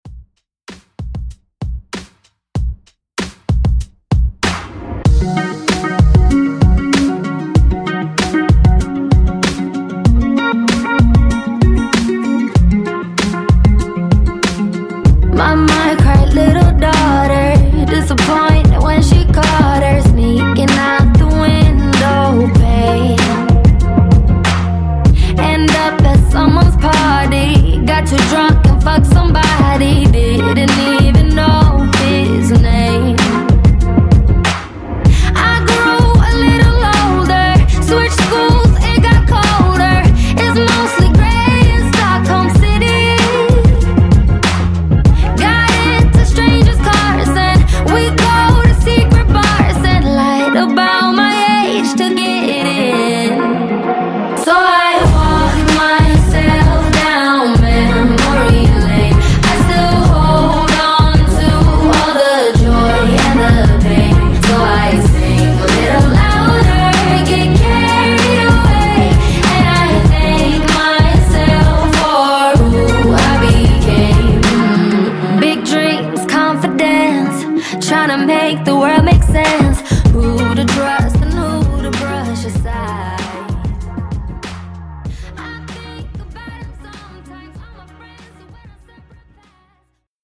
Genre: DANCE
Clean BPM: 125 Time